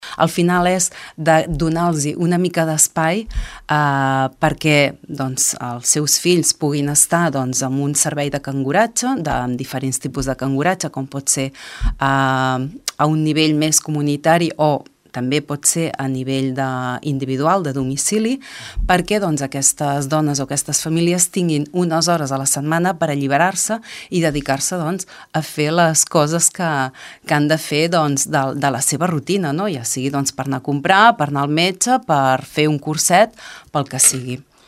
Marta Paset, regidora d’ERC a Tordera i consellera de drets i serveis a les persones al consell comarcal.